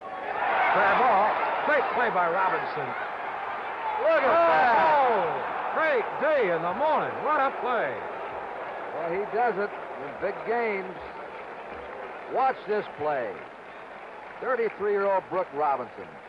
Robinson earned MVP honors as he displayed one of the greatest World Series performances in all of baseball, portrayed by this call from Curt Gowdy.
Brooks-Robinson-World-Series-Highlight-1.mp3